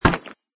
metalstone3.mp3